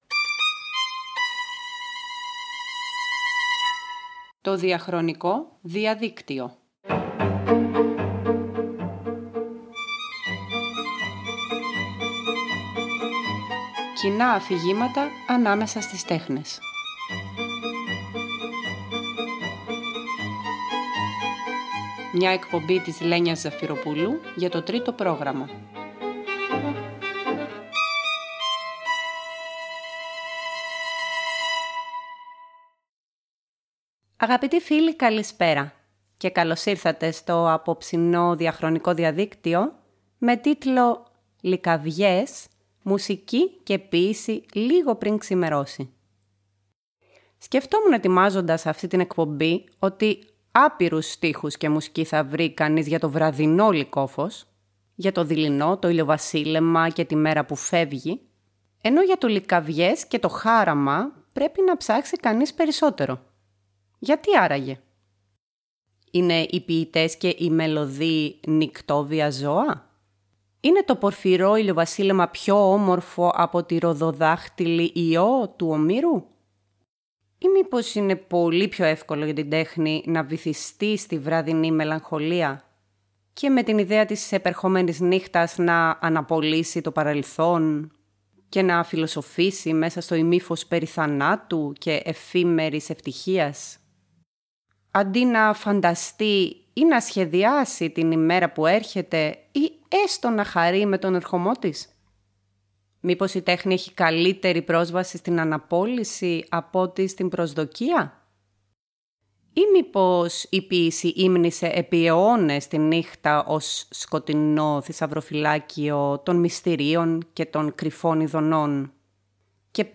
Λυκαυγές – ποίηση και μουσική λίγο πριν ξημερώσει